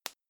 CZ20 bubble wrap